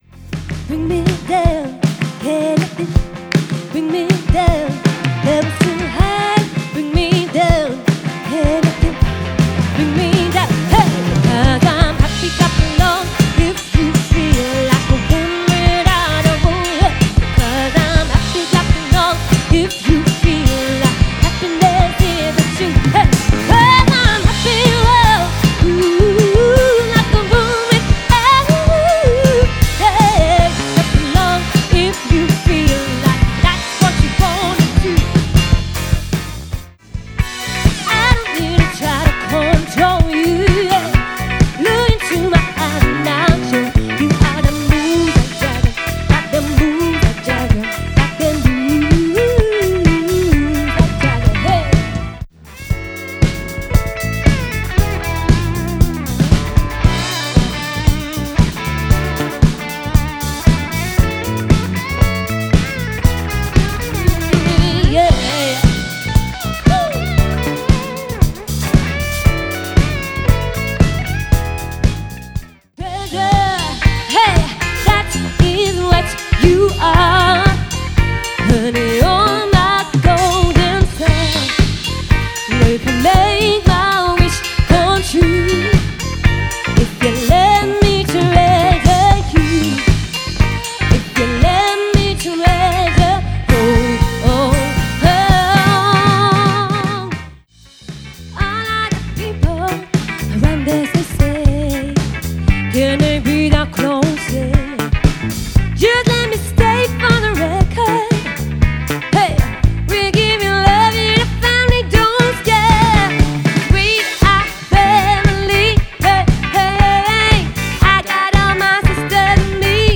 Pop – Soul – Rock – Disco
fun, upbeat and popular live sets for all generations